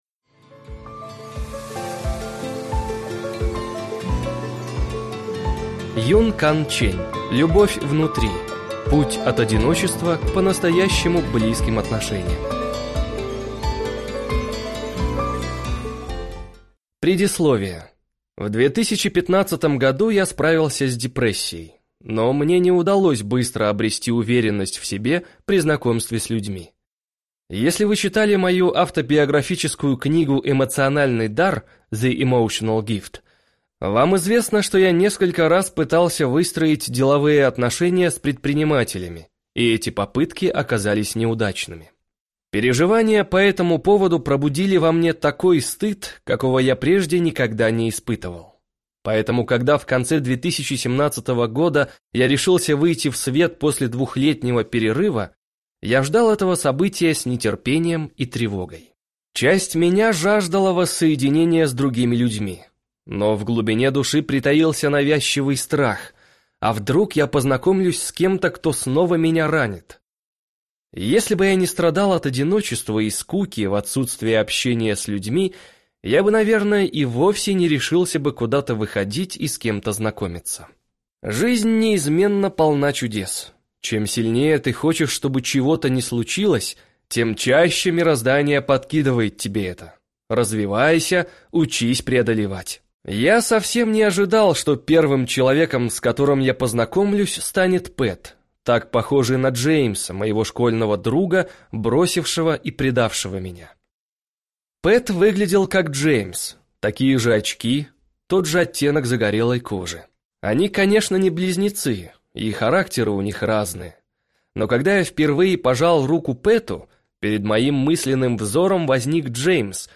Аудиокнига Любовь внутри. Путь от одиночества к по-настоящему близким отношениям | Библиотека аудиокниг